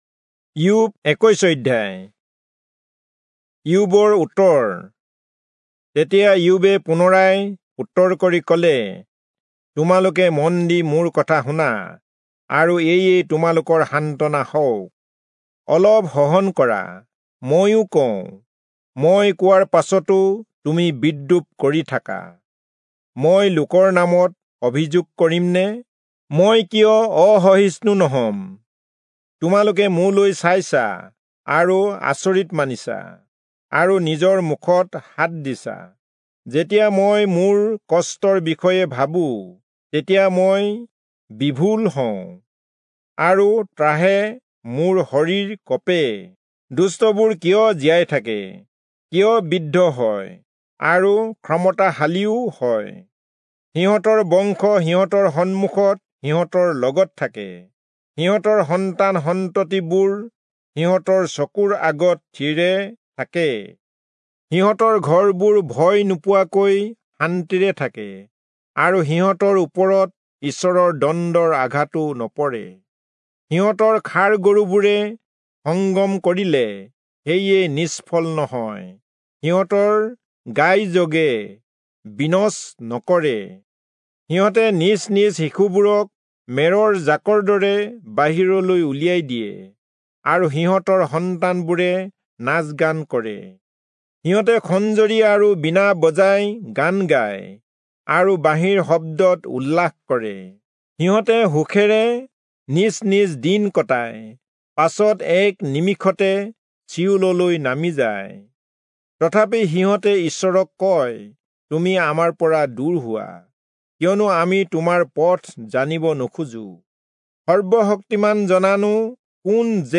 Assamese Audio Bible - Job 26 in Ervta bible version